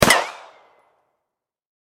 Звук рикошета пули в реальной жизни